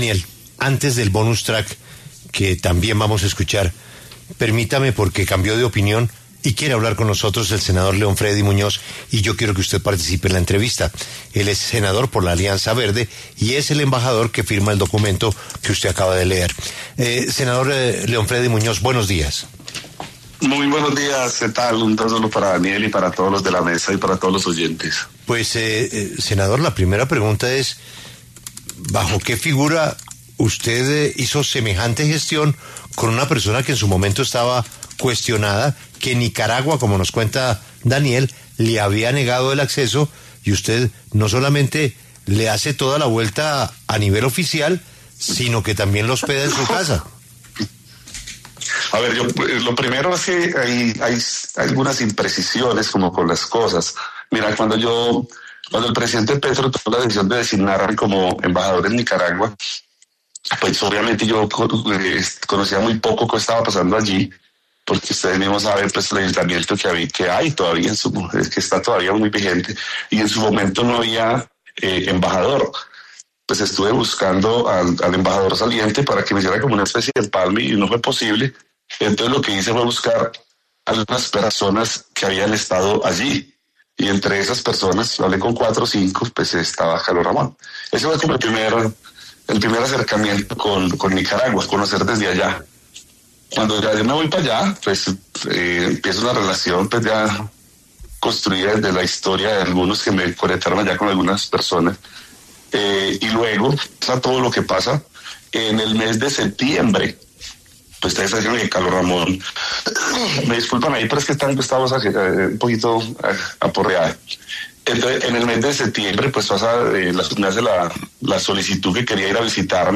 El senador León Fredy Muñoz, senador de la República, habló en El Reporte Coronell de La W sobre la residencia de Carlos Ramón González en la Embajada de Colombia en Nicaragua.